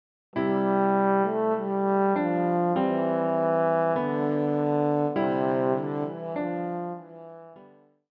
deutsches Weihnachtslied